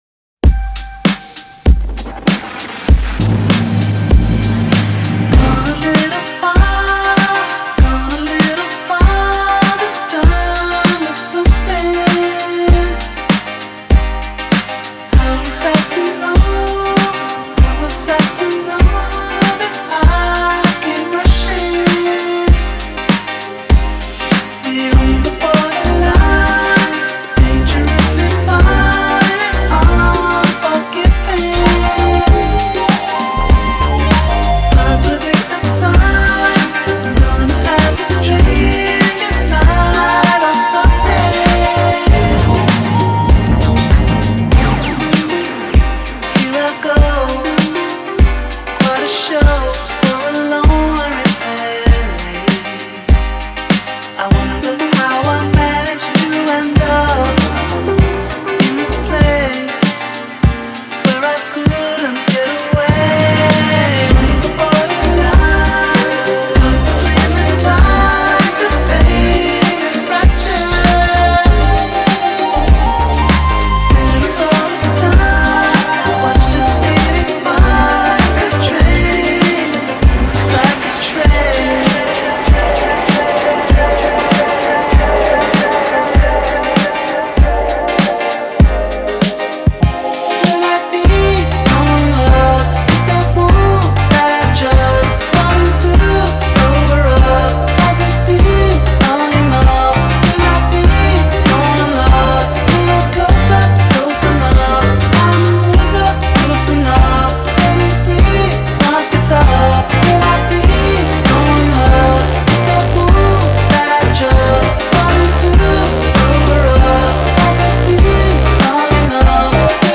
این آهنگ برای ادیت هایی با فضای خاص و تنهایی بسیار مناسبه